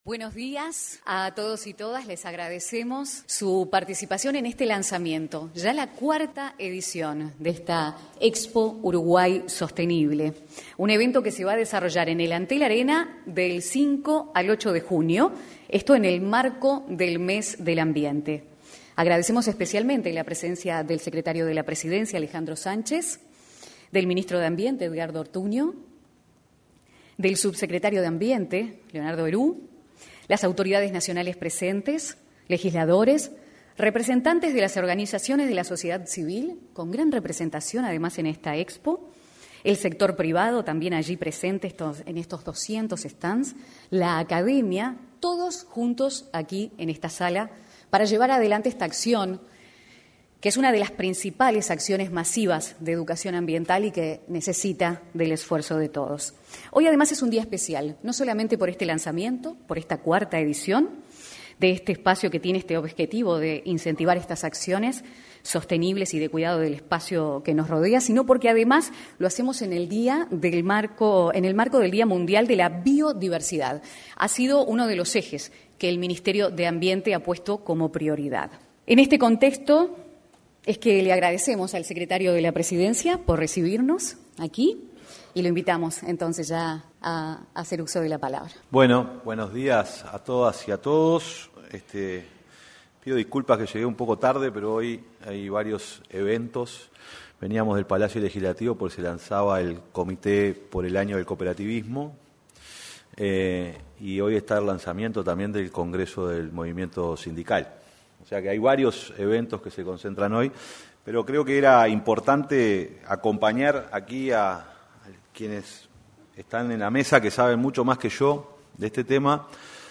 Este jueves 22, se realizó, en el auditorio del anexo a la Torre Ejecutiva, la presentación de la 4.ª edición de la Expo Uruguay Sostenible.